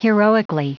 Prononciation du mot heroically en anglais (fichier audio)
Prononciation du mot : heroically